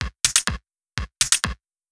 PERCUSSN020_DISCO_125_X_SC3.wav